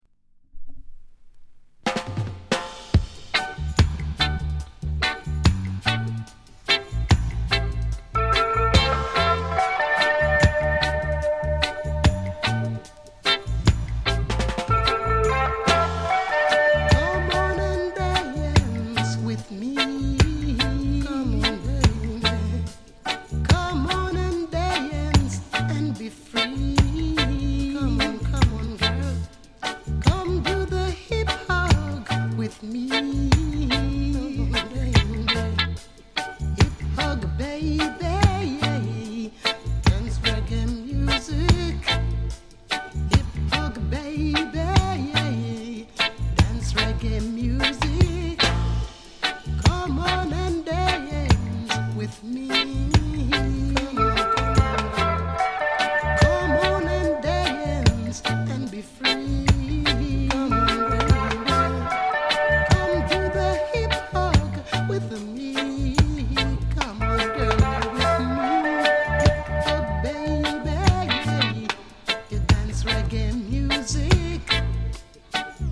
ソウルフル *